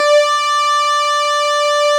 Added synth instrument
snes_synth_062.wav